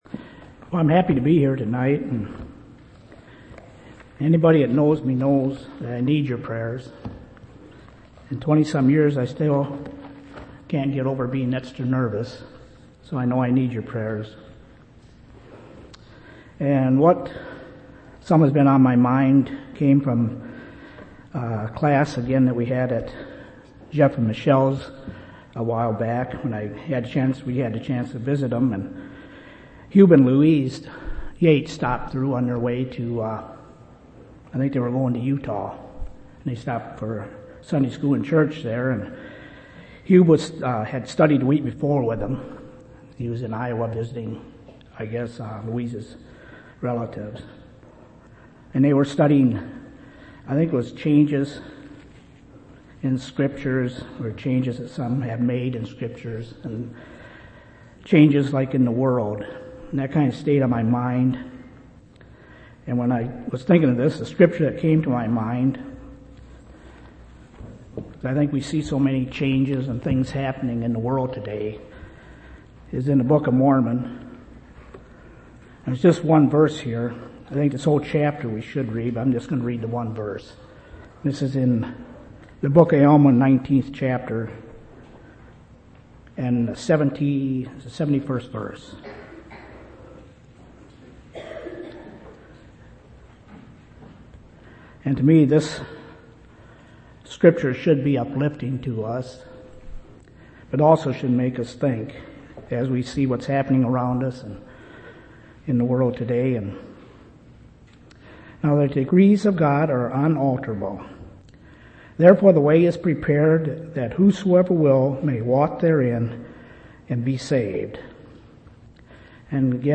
6/27/2004 Location: Temple Lot Local Event